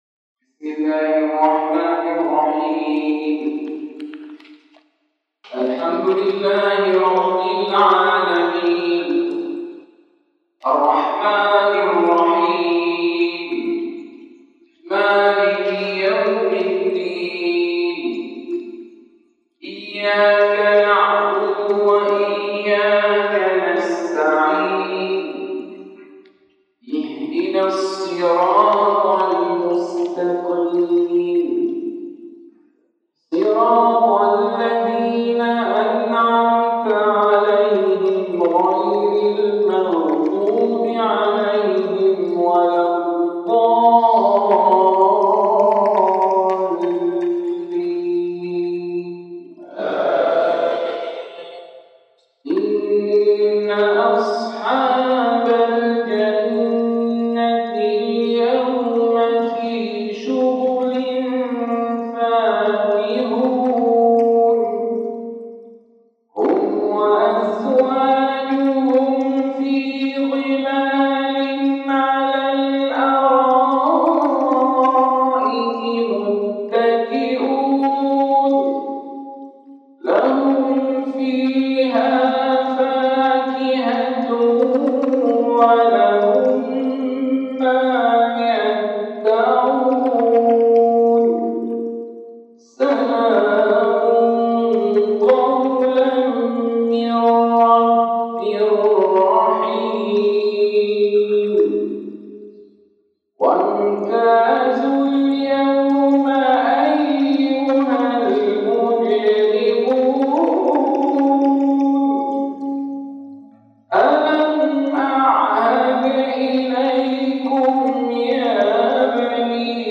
تلاوة